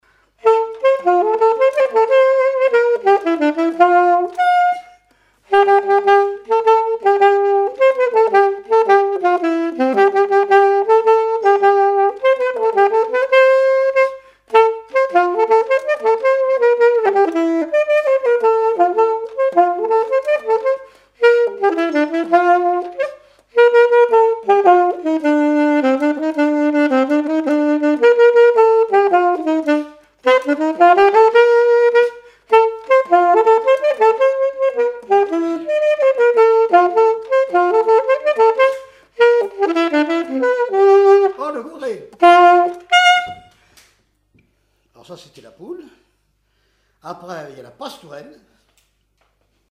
Mémoires et Patrimoines vivants - RaddO est une base de données d'archives iconographiques et sonores.
danse : quadrille : poule
témoignages et instrumentaux
Pièce musicale inédite